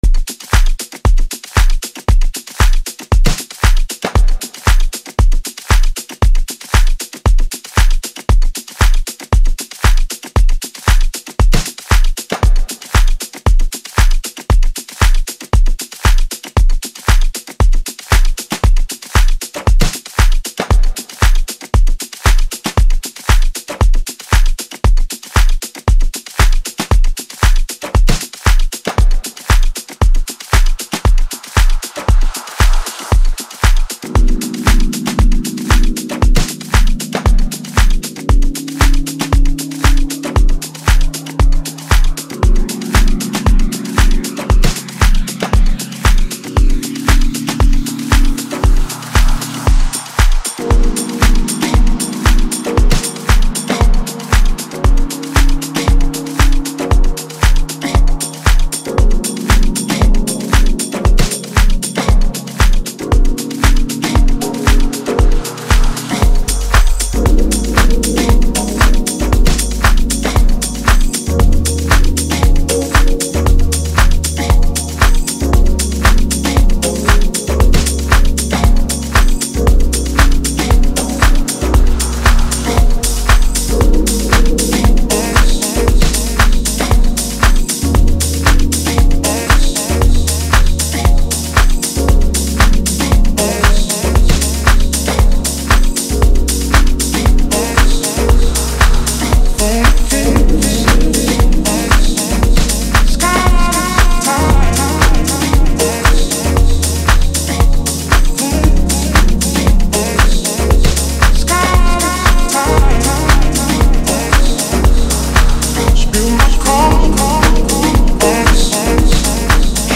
Home » Amapiano
refreshing vibe